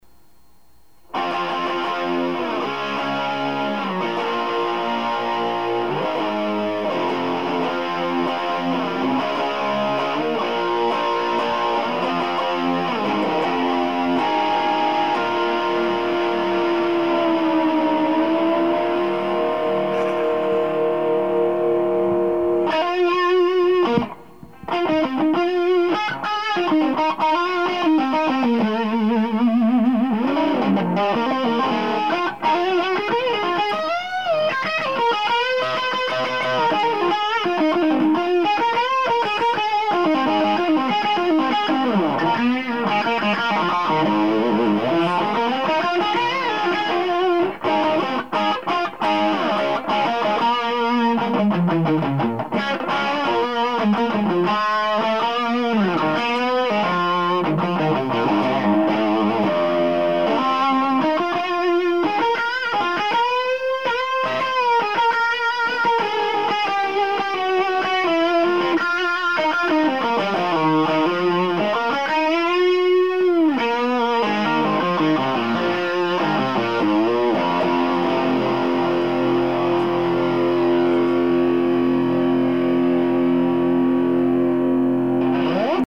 This was at a summer backyard jam party at a friend's house a few years ago.
The guitars were plugged directly into the Music Man 410-65 for clean and through the effects rack for other tones.
So this is actually a 3-channel guitar set-up.
A sample mp3 file (mono from a cassette) of me noodling around on this rig at the jam can be found here:
The sound clip was played on the Les Paul.